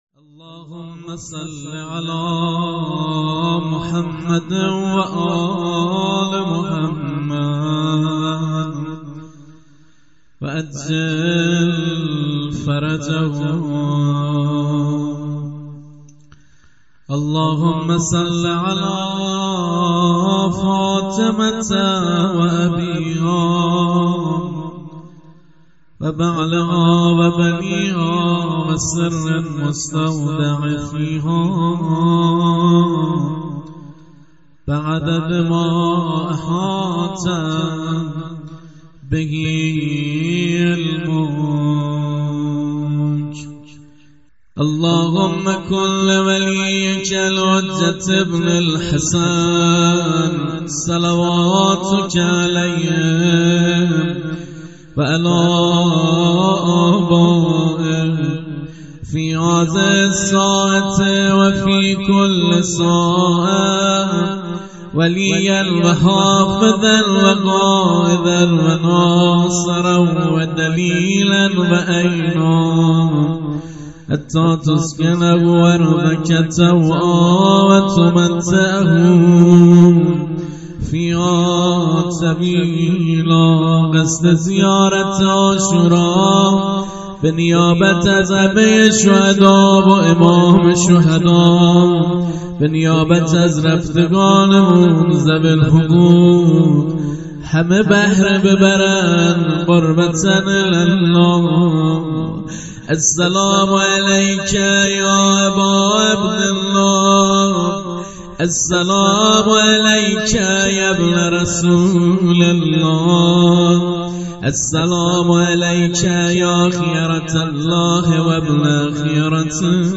جلسه زیارت عاشورای هفتگی هیئت شهدای گمنام93/07/14
مداحی